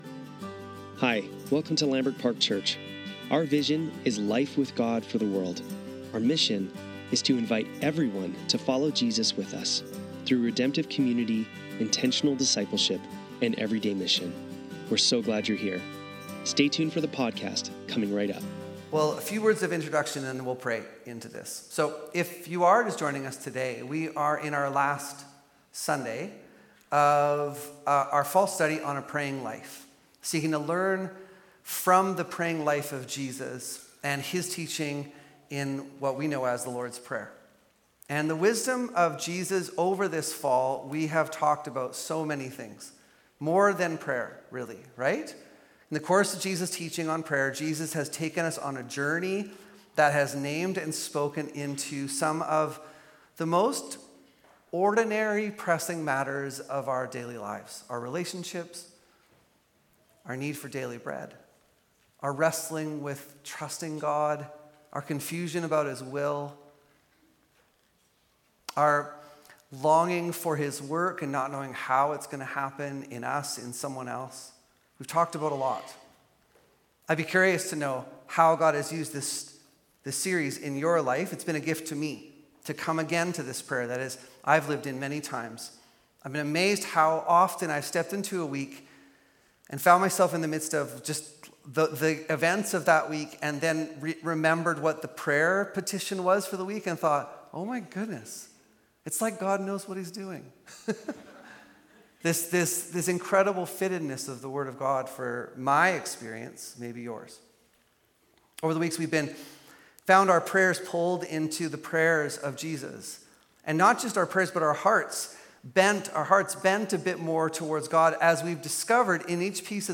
Lambrick Sermons | Lambrick Park Church
Sunday Service - November 30, 2025